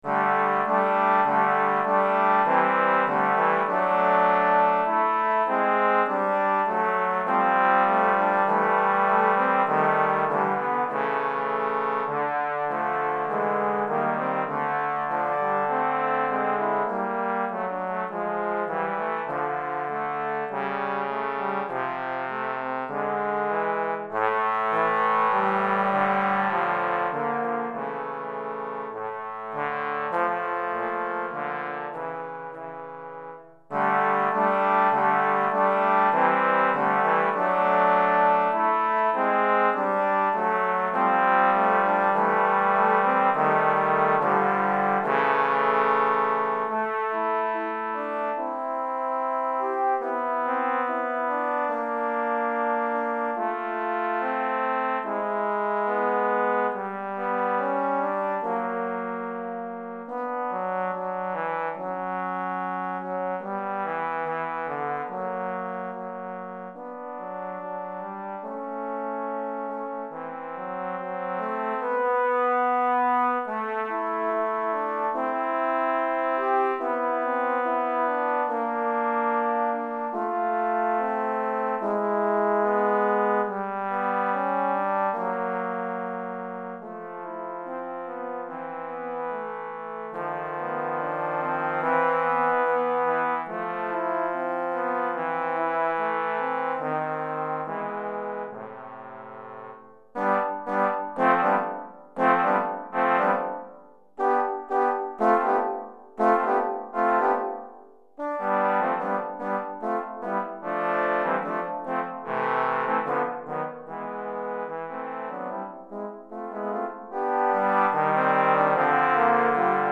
3 mouvements : décidé, très modéré, allegro